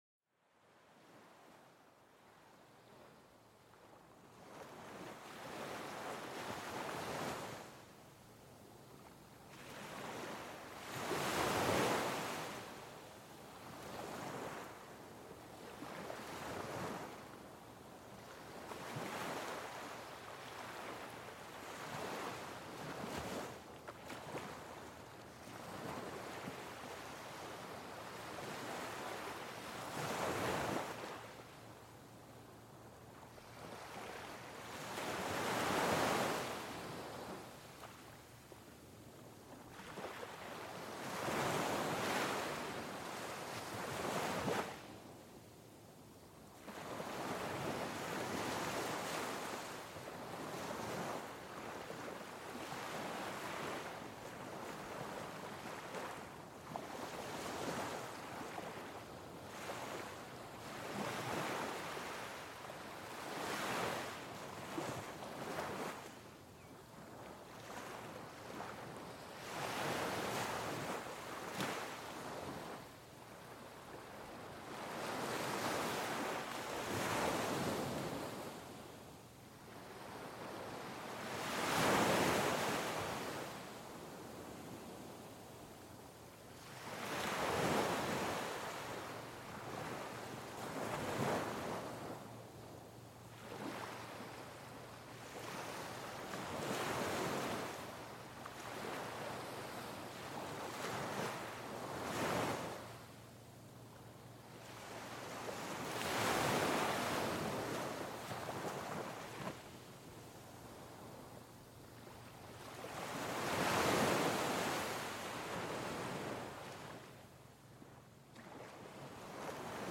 Sumérgete en el corazón del océano y déjate mecer por el sonido tranquilizador de las olas. Este episodio te ofrece un viaje sonoro único, donde cada ola que rompe te acerca un poco más a la serenidad.